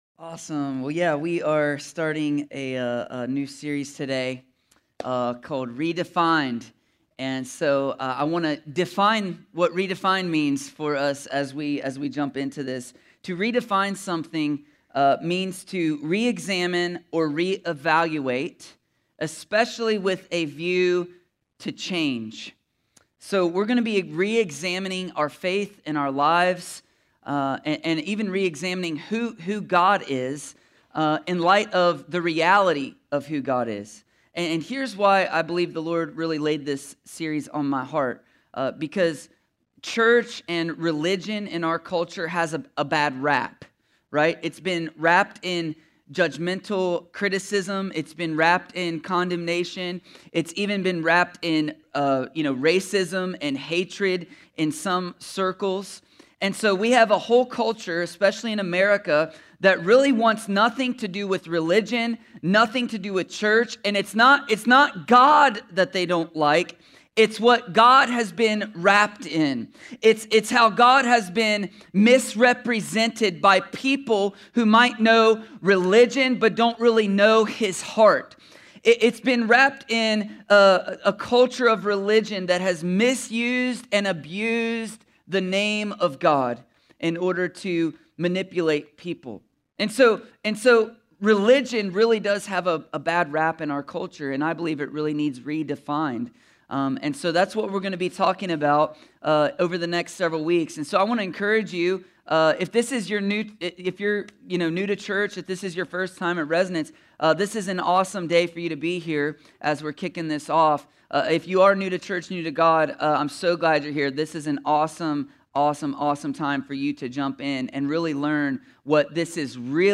A sermon from the series “(RE)DEFINED.”…